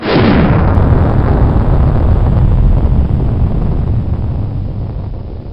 sing bowl wave bird relaxtion meditation music